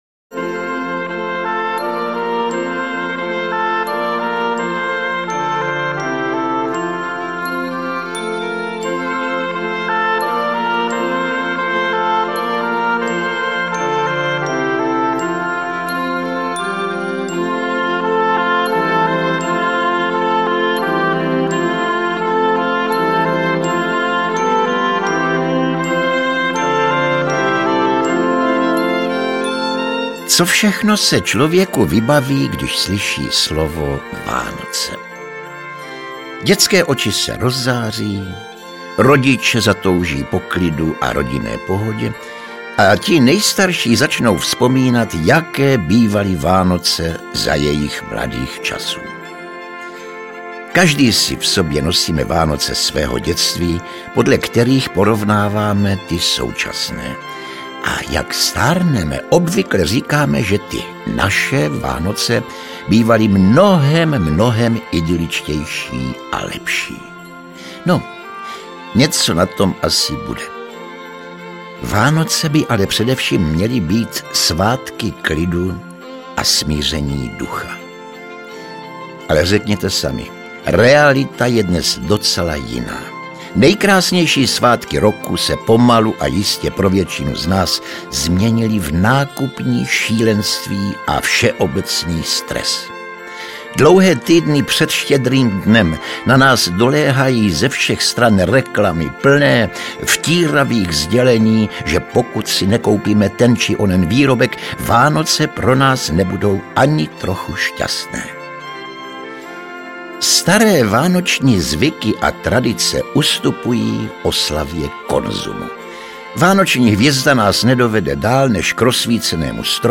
Vánoční zamyšlení Josefa Somra doprovázené nádhernými vánočními koledami. Oblíbený herec poutavě vypráví o významu jednotlivých svátků, o původu vánočního stromku i dárků, o vánočních pokrmech u nás i ve světě. Pásmo příběhů a koled navodí nádhernou atmosféru a připomene všem posluchačům Vánoce jejich dětství.
AudioKniha ke stažení, 7 x mp3, délka 1 hod., velikost 54,6 MB, česky